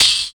71 HAT.wav